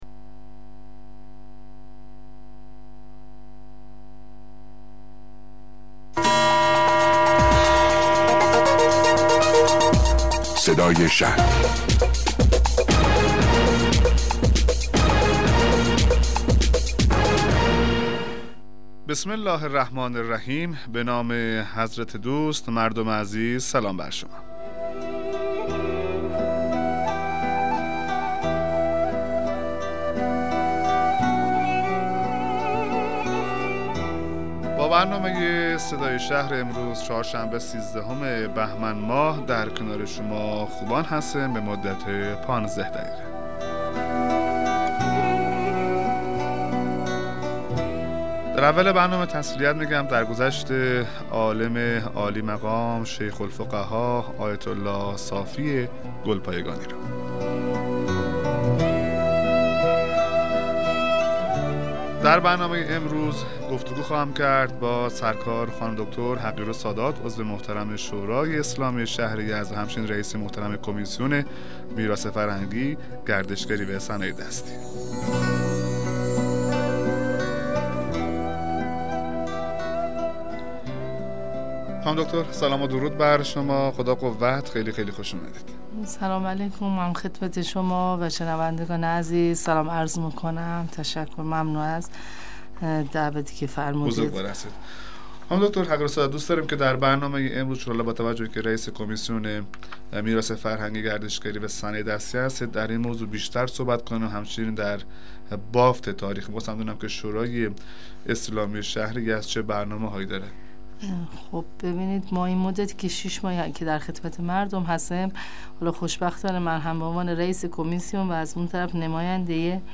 مصاحبه رادیویی برنامه صدای شهر با حضور بی بی فاطمه حقیرالسادات رییس کمیسیون گردشگری شورای اسلامی شهر یزد